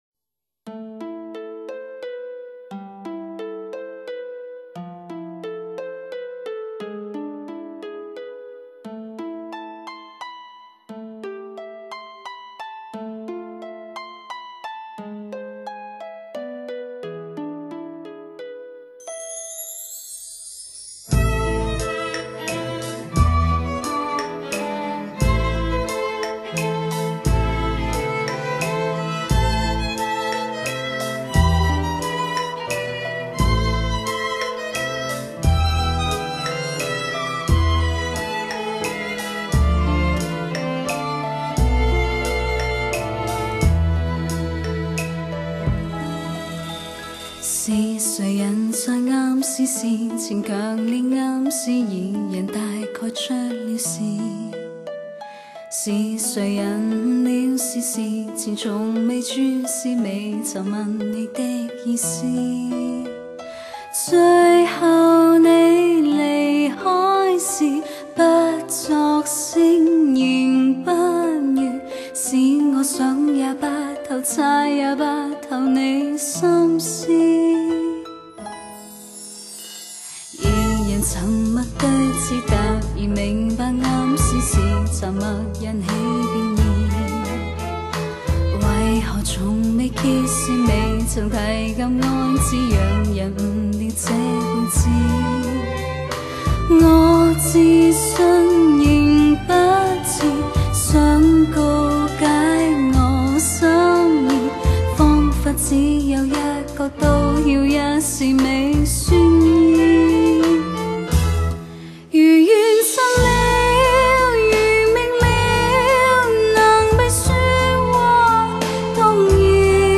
配乐的铺排相当丰富，配合目前世界上都少有、极具监场感的双声道环绕混音技术给众多发烧友带来奇特的感受。